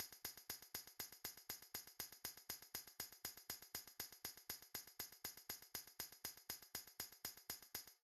1 channel
tamborine.ogg